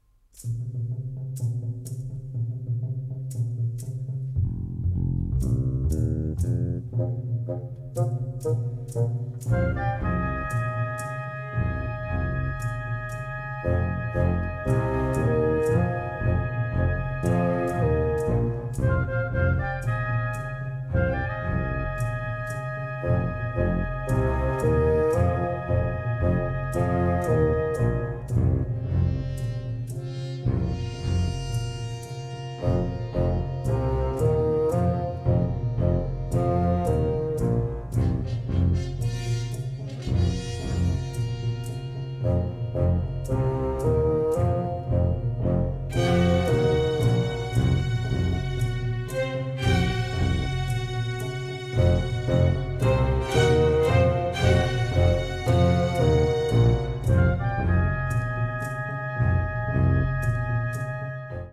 symphonic ensemble